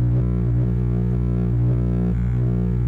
Fridge_Hum.wav